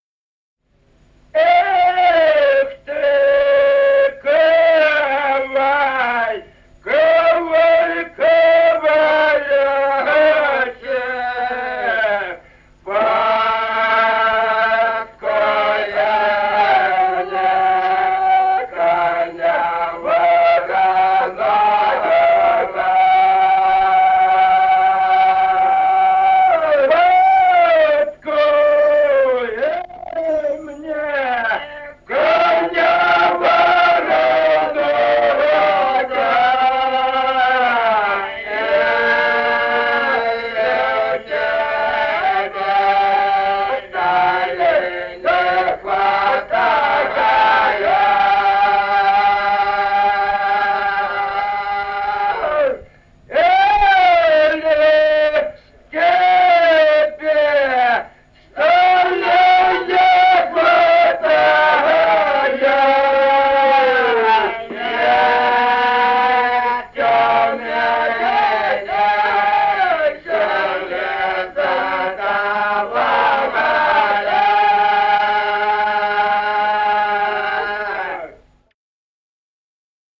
Песни села Остроглядово в записях 1950-х годов